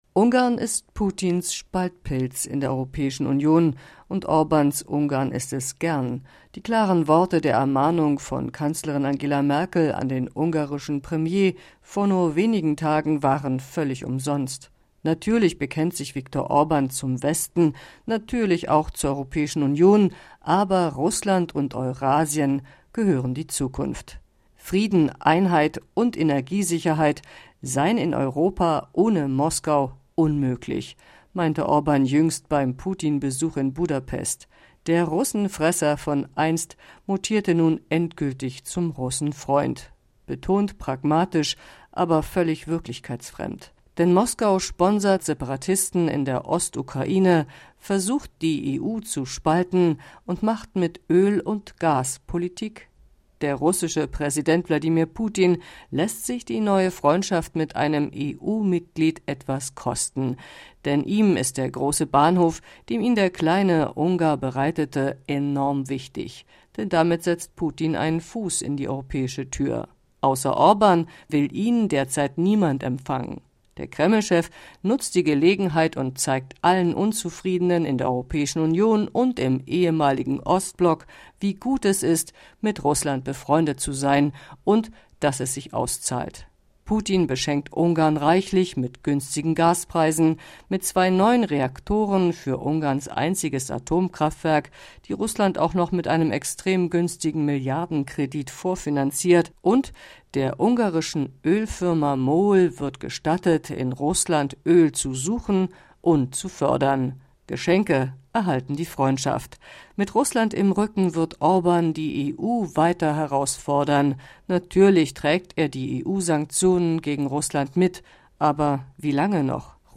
Kommentar: Ungarn ist Putins Spaltpilz - ARD Wien